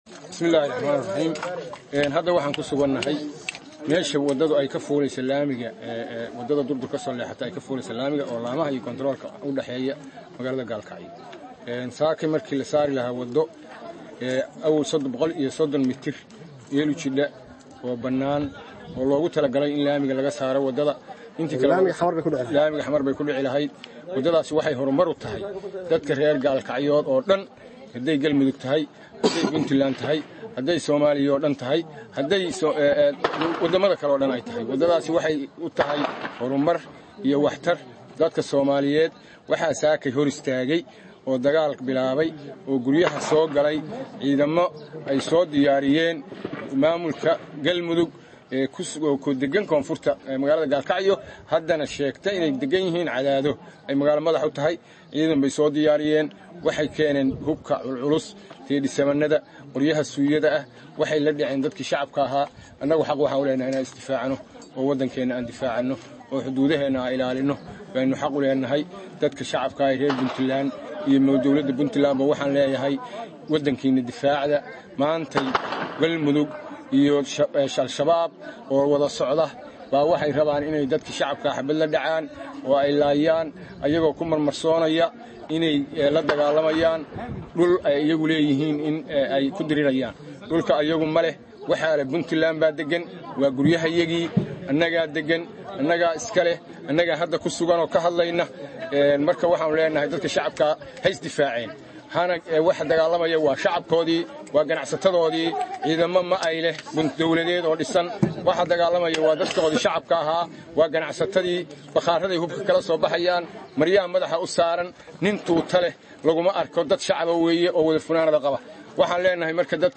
Galkacyo(INO))Taliyaha Ciidanka birmadka gobolka Mudug Cabdirshiid Xasan Xaashi (Gibi-Gibi) oo warkooban siiyay saxaafada ayaa ka hadlay Xaalada Magaalada iyo Dagaalkii saaka cida ka dambeysay.